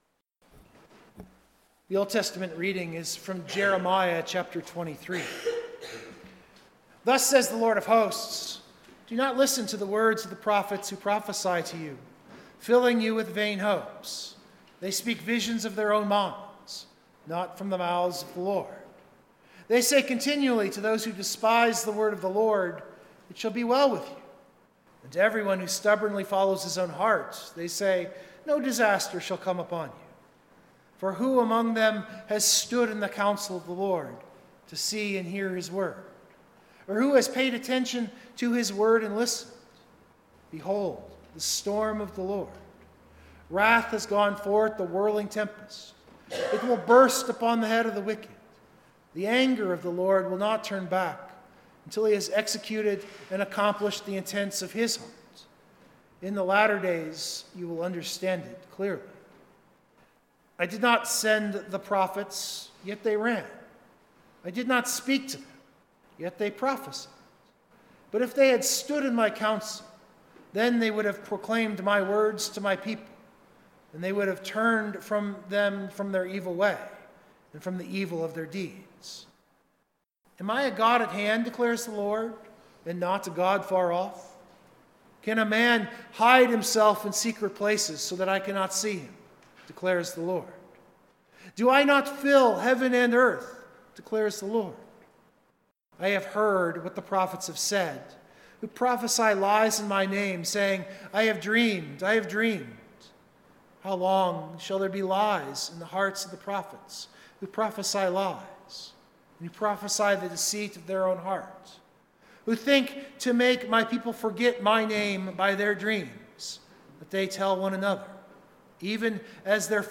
Full Sermon Draft